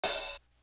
Crushed Linen Open Hat.wav